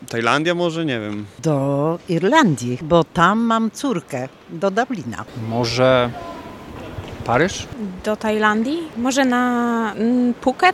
Zapytaliśmy podróżnych o to gdzie chcieliby polecieć z Wrocławskiego Portu Lotniczego.
Sonda-1.mp3